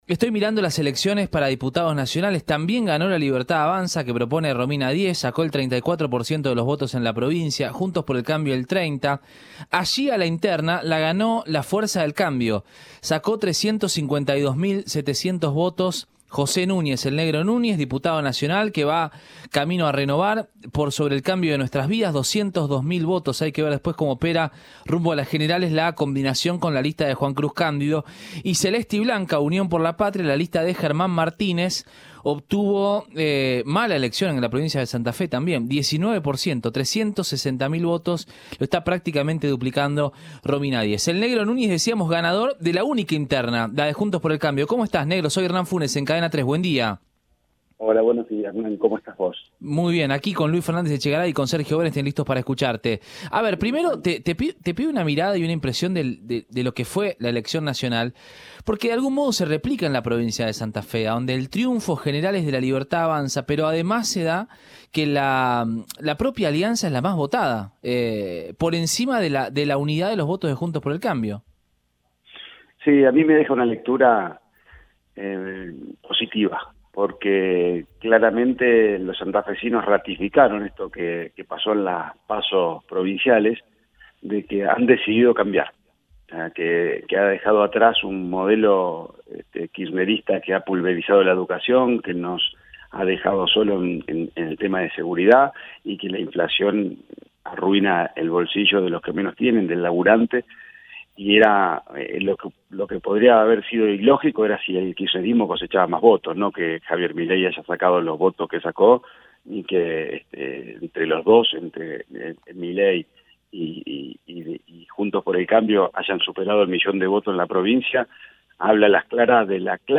“En Santa Fe ya se notaba el enojo de la gente con la política y con nosotros porque veían discutir a Horacio y Patricia”, expresó Núñez en Radioinforme 3, por Cadena 3 Rosario.